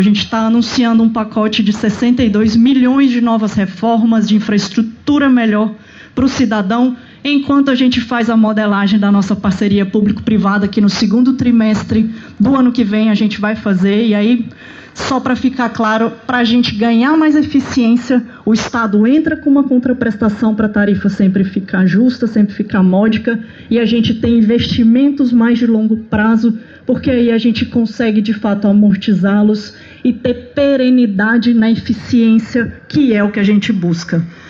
A declaração foi dada em São Sebastião durante cerimônia de entrega de duas balsas que foram modernizadas, cujos trabalhos custaram R$ 15 milhões.